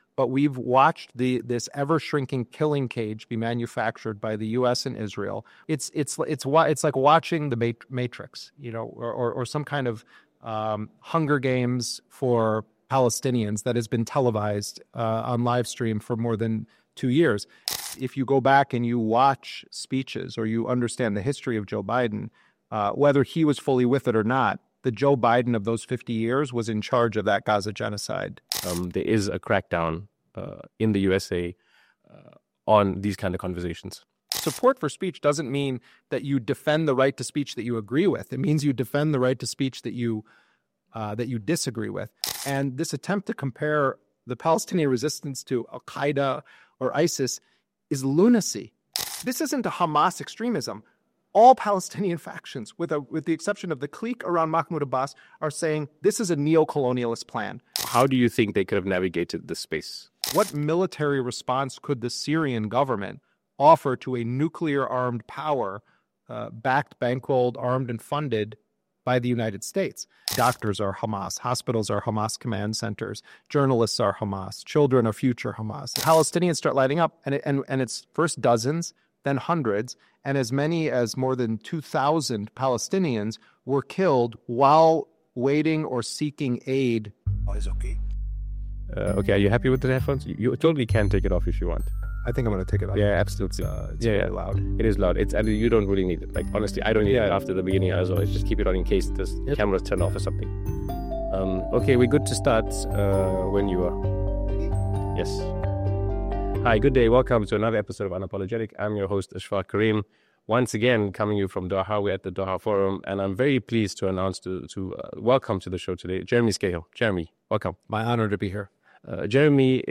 In this UNAPOLOGETIC episode from the Doha Forum, investigative journalist Jeremy Scahill examines how Israel has carried out a campaign that many experts believe meets the legal and moral definitions of genocide in Gaza, while simultaneously insisting that Palestinians must not resist and that neighbouring states must demilitarise. Scahill situates Israel’s assault within a wider history of US militarism, privatised warfare, and the global security industry, showing how Gaza has become a testing ground for surveillance, weapons and siege tactics.